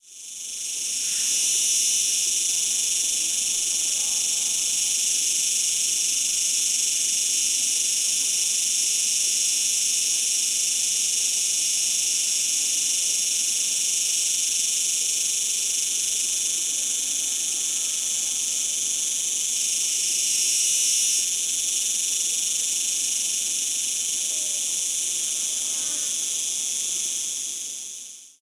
Ambiente de campo con chicharras
Sonidos: Animales Sonidos: Rural